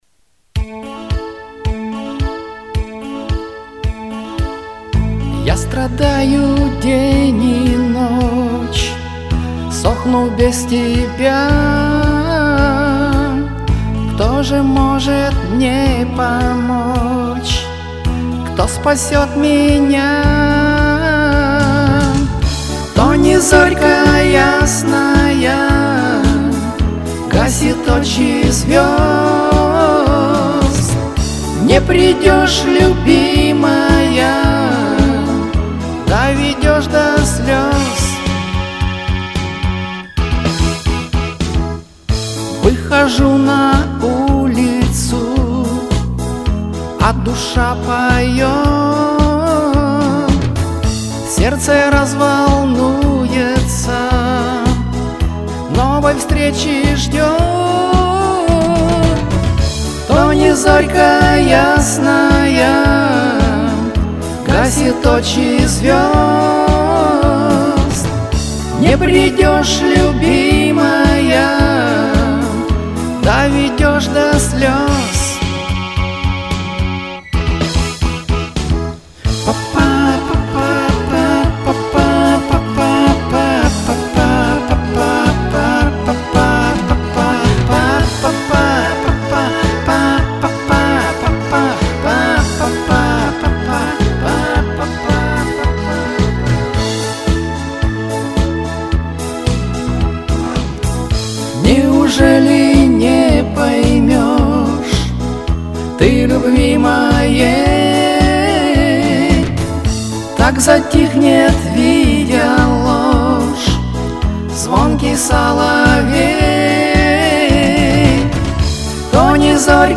Эта песня, как мне кажется, должна звучать мягко.